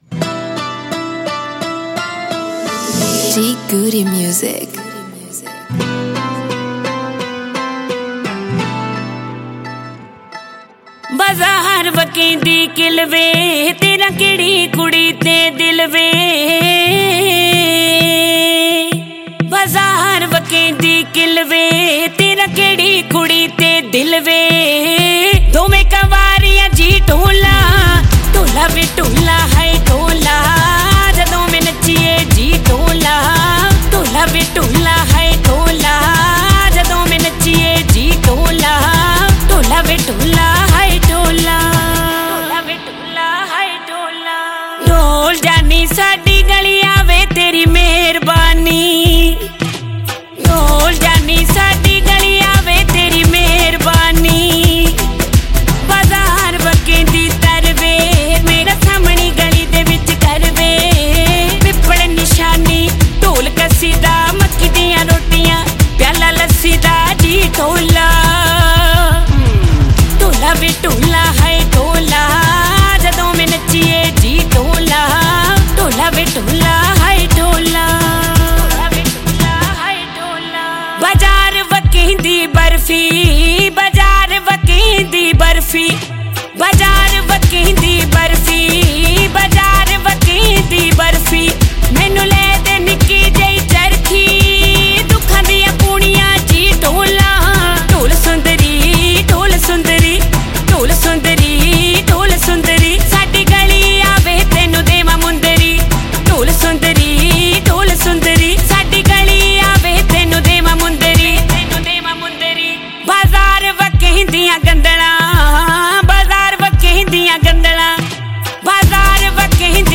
Punjabi